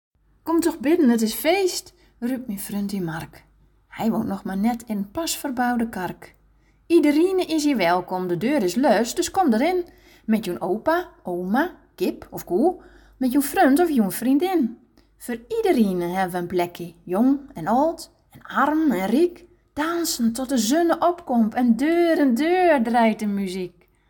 De tekst op de posters is ook ingesproken!